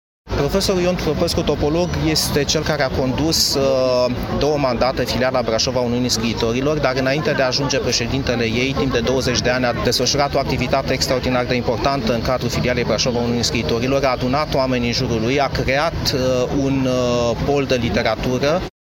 evocată la Târgul de Carte Gaudeamus Radio România